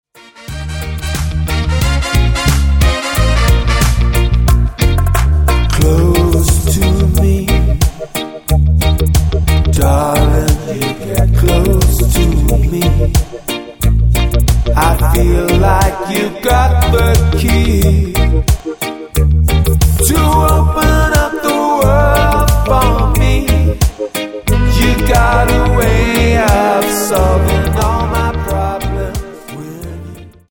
Tonart:Eb Multifile (kein Sofortdownload.